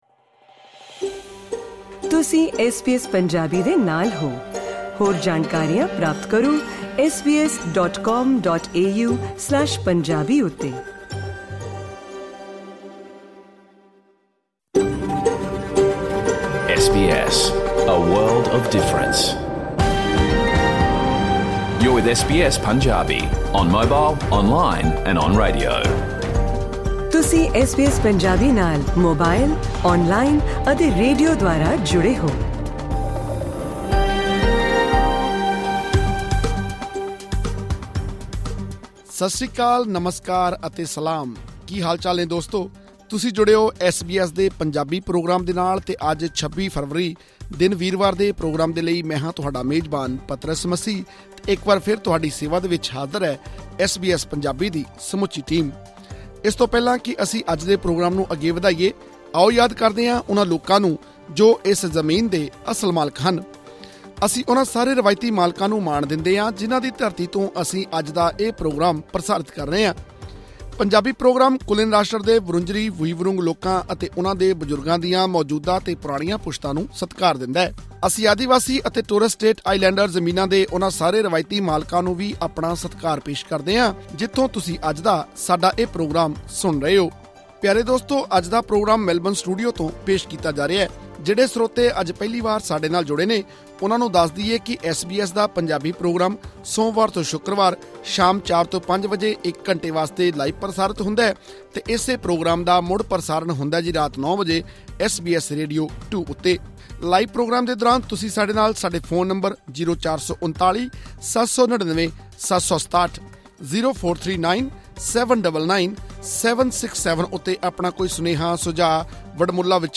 ਸੁਣੋ ਐਸ ਬੀ ਐਸ ਪੰਜਾਬੀ ਦਾ ਪੂਰਾ ਰੇਡੀਓ ਪ੍ਰੋਗਰਾਮ
ਪ੍ਰੋਗਰਾਮ 'ਚ ਆਸਟ੍ਰੇਲੀਆ ਦੀ ਪਹਿਲੀ ਤੇਜ਼ ਰਫਤਾਰ ਰੇਲ ਦੇ ਪ੍ਰੋਜੈਕਟ ਵਿੱਚ ਫੈਡਰਲ ਸਰਕਾਰ ਦੀ ਵਿਕਾਸ ਯੋਜਨਾ ਬਾਬਤ ਖਾਸ ਰਿਪੋਰਟ ਵੀ ਸ਼ਾਮਿਲ ਹੈ। ਇਹ ਸਾਰੀ ਜਾਣਕਾਰੀ, ਪੰਜਾਬੀ ਸੰਗੀਤ ਅਤੇ ਗੱਲਾਂਬਾਤਾਂ ਦਾ ਅਨੰਦ ਮਾਨਣ ਲਈ ਇਹ ਪੌਡਕਾਸਟ ਸੁਣੋ....